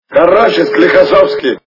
» Звуки » Из фильмов и телепередач » Кавказская пленница - Короче Склихосовский
При прослушивании Кавказская пленница - Короче Склихосовский качество понижено и присутствуют гудки.